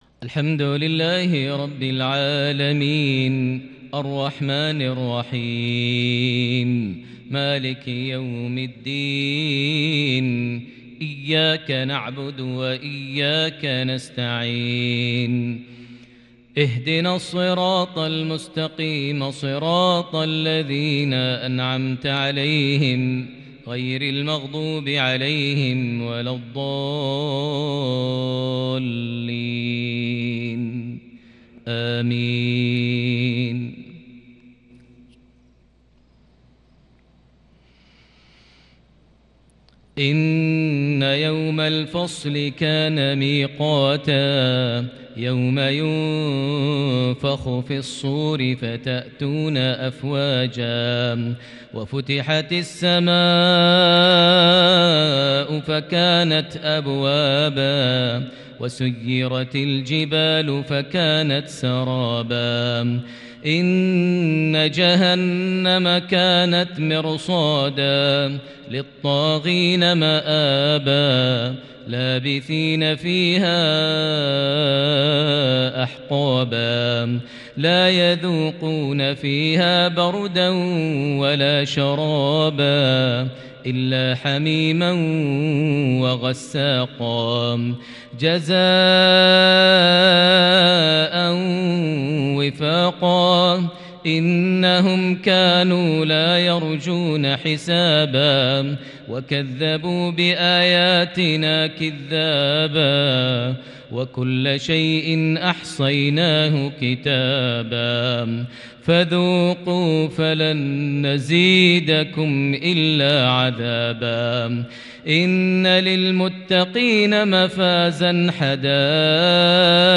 مغربية رائعة بانتقاءات متفردة من سورتي النبأ - عبس | 24 جمادى الآخر 1442هـ > 1442 هـ > الفروض - تلاوات ماهر المعيقلي